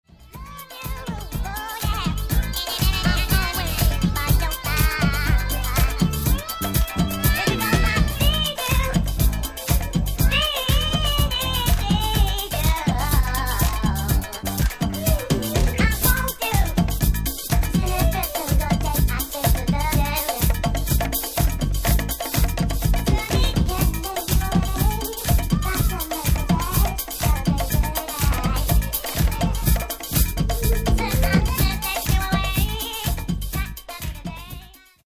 Genere:   Disco | Funk | Soul